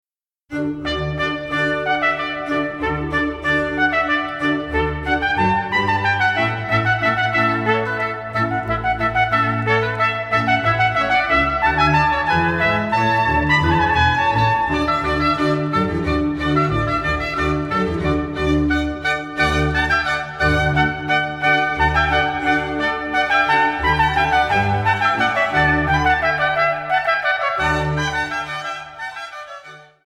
für Trompete, 2 Oboen, Streicher & B.c.